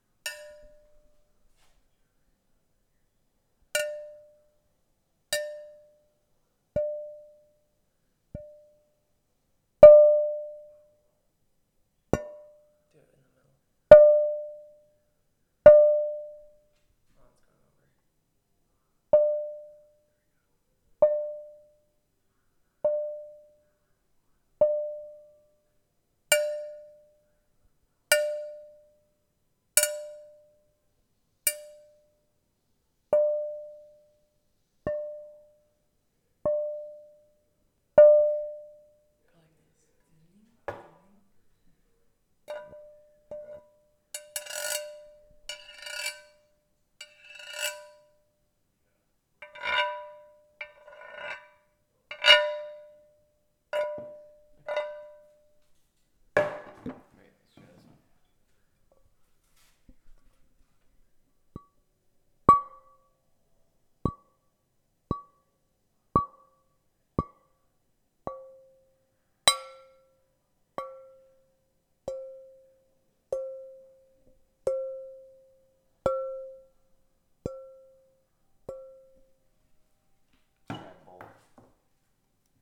glass_hit_bowls
Bell Boing Bowl Ding Dong Donk Glass Hit sound effect free sound royalty free Sound Effects